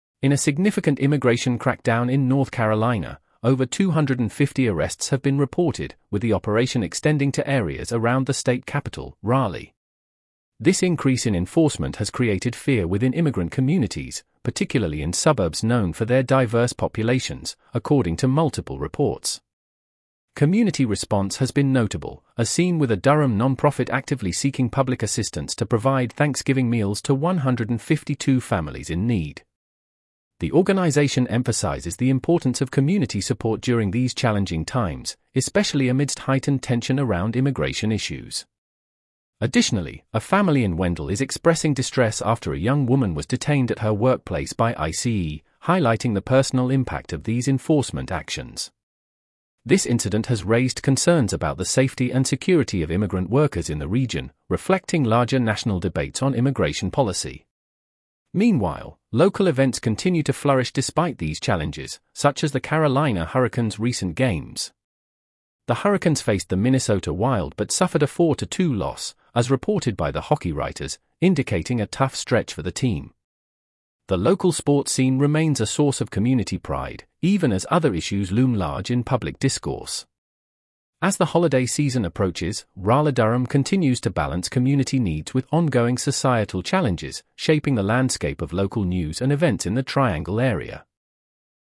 Raleigh-Durham News Summary